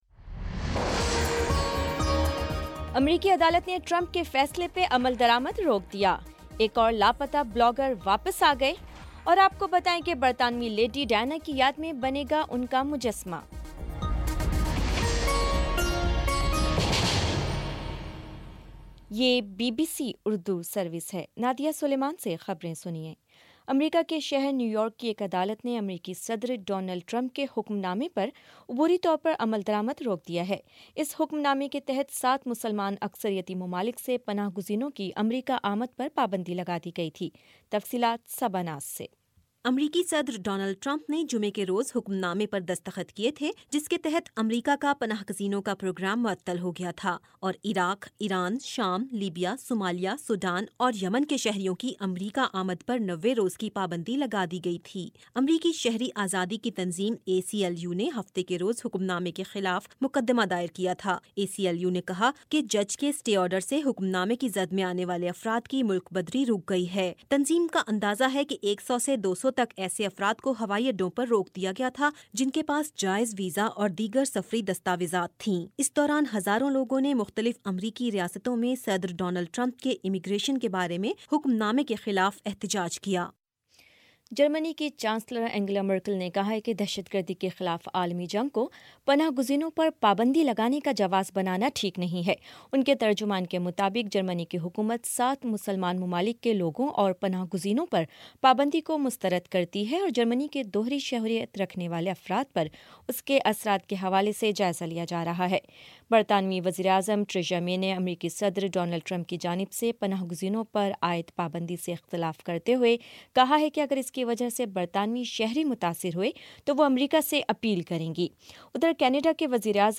جنوری 29 : شام سات بجے کا نیوز بُلیٹن